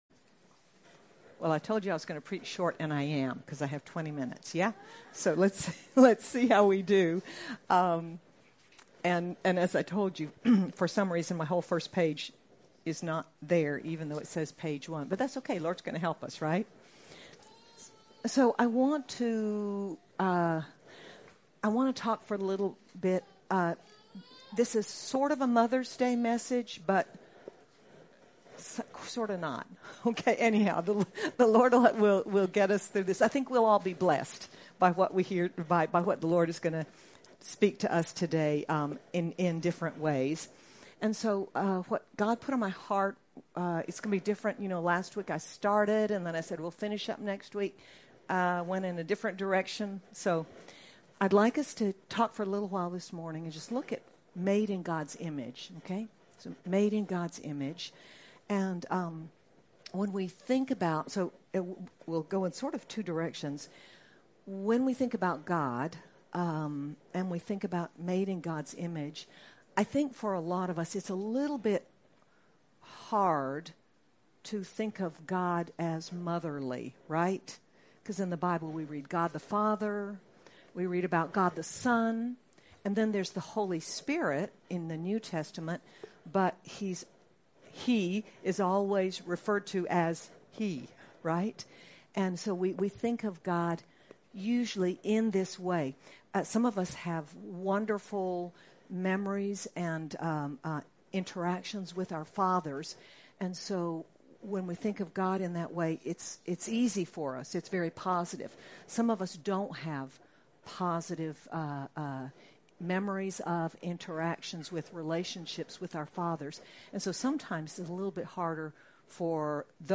May 12, 2025 Made in God’s Image MP3 SUBSCRIBE on iTunes(Podcast) Notes Discussion God has made us in His image—both male and female—both in His image. Sermon by